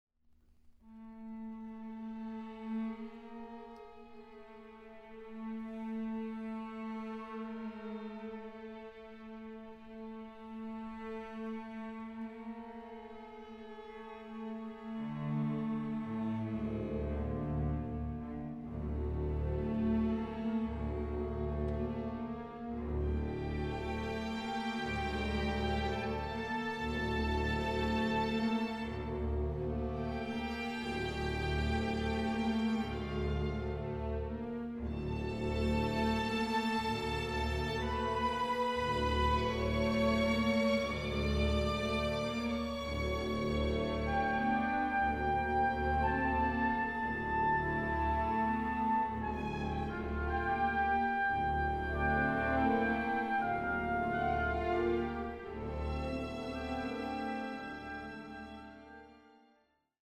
Recording: Katharina-Saal, Stadthalle Zerbst, 2025
für großes Orchester